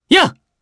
Arch-Vox_Attack3_jp.wav